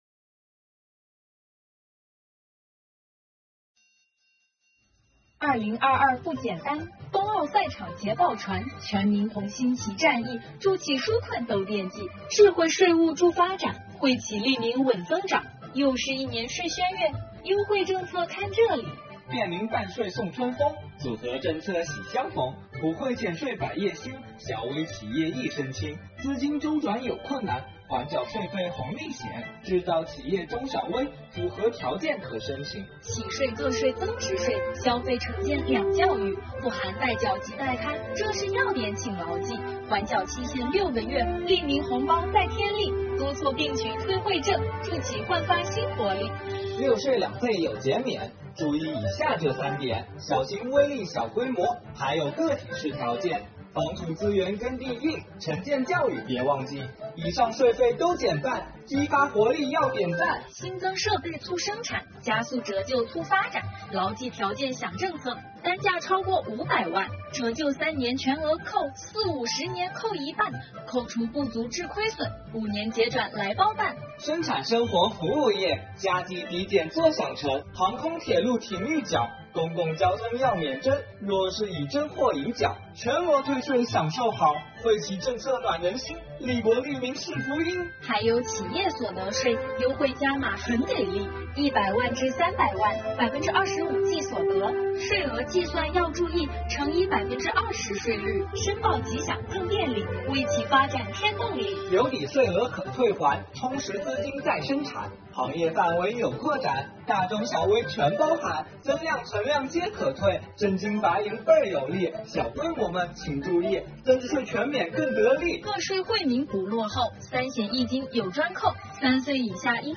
【税务快板】税费政策红利多，惠企利民您听好！